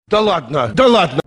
Звуки популярных видео на Youtube: Якубович говорит